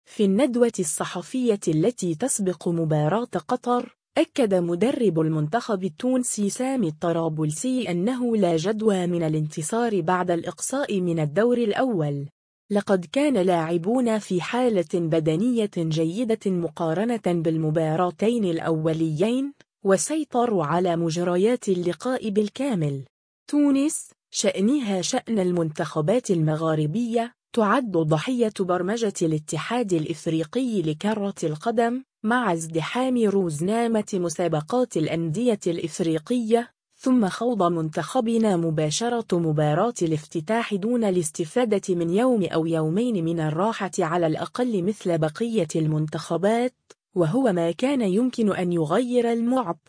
في الندوة الصحفية التي تسبق مباراة قطر، أكد مدرب المنتخب التونسي سامي الطرابلسي أنّه “لا جدوى من الانتصار بعد الإقصاء من الدور الأول.